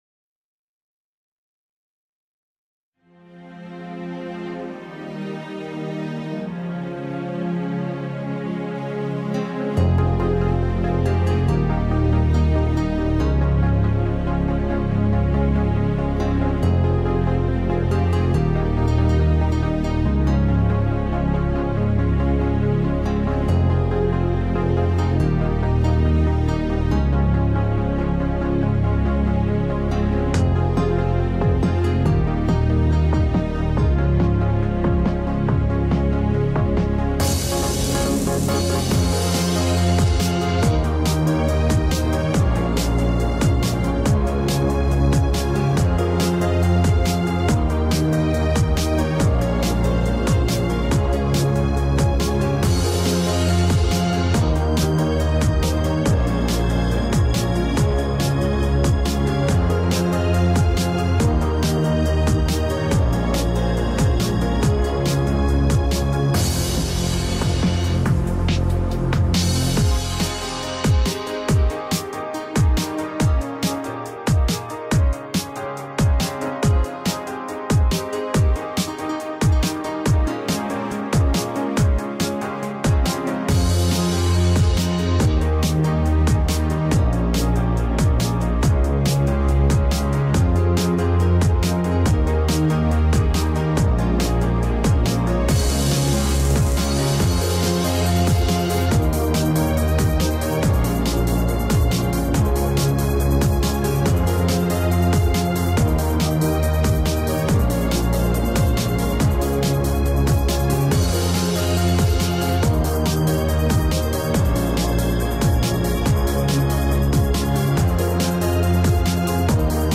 минусовка версия 225607